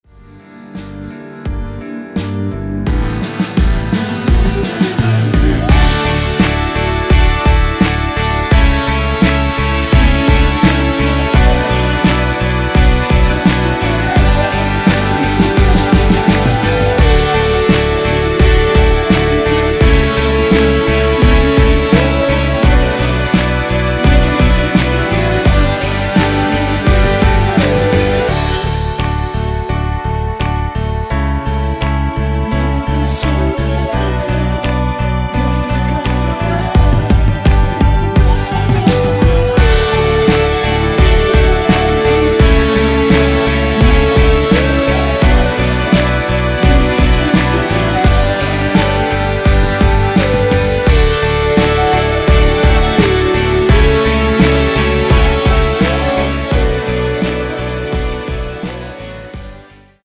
원키에서(-1)내린 코러스 포함된 MR입니다.
D
앞부분30초, 뒷부분30초씩 편집해서 올려 드리고 있습니다.
중간에 음이 끈어지고 다시 나오는 이유는